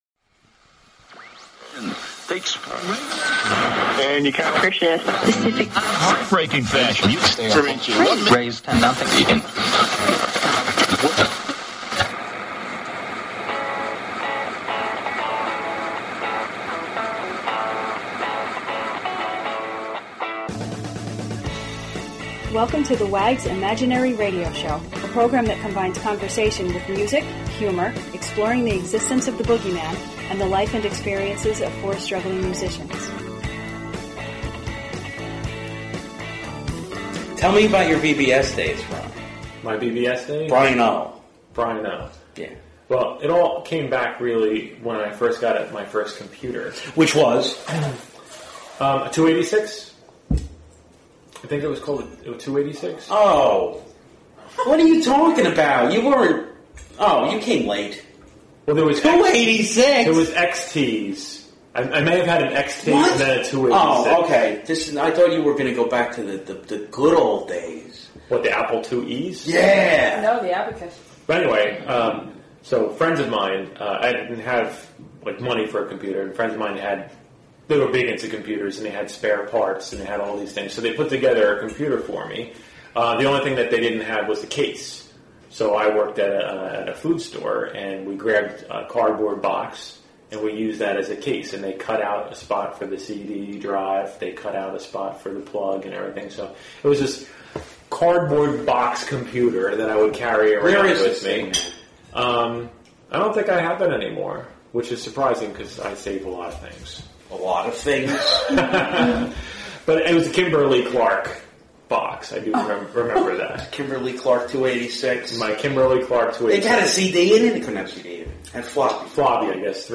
Talk Show Episode, Audio Podcast, The_Wags_Imaginary_Radio_Show and Courtesy of BBS Radio on , show guests , about , categorized as
It is a podcast that combines conversation with music, humor & the life experiences of four struggling musicians from New Jersey.